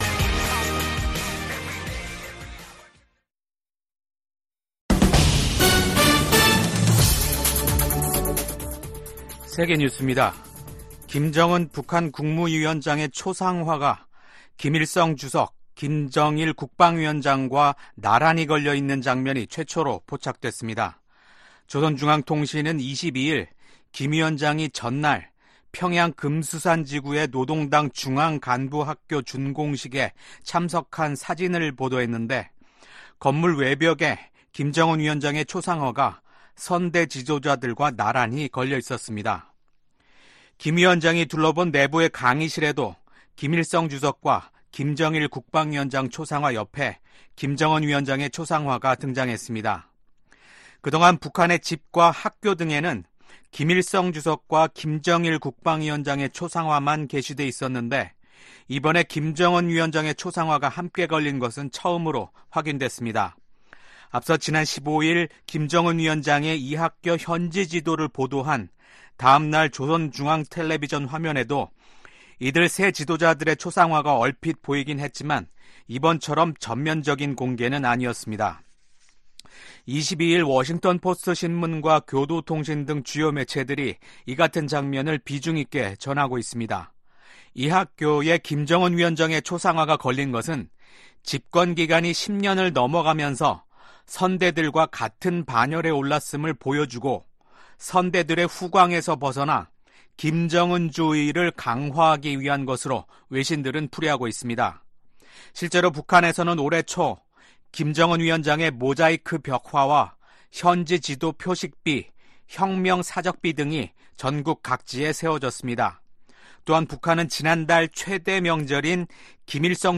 VOA 한국어 아침 뉴스 프로그램 '워싱턴 뉴스 광장' 2024년 5월 23일 방송입니다. 토니 블링컨 미국 국무장관이 러시아에 대한 북한의 직접 무기 지원을 비판했습니다. 미국 국방부는 미국과 동맹들이 한반도 긴장을 고조시킨다는 러시아의 주장을 일축하고, 미한일 협력이 역내 평화와 안보, 안정을 가져왔다고 강조했습니다. 미국과 영국, 호주에 이어 캐나다 정부도 북한과 러시아의 무기 거래를 겨냥한 제재를 단행했습니다.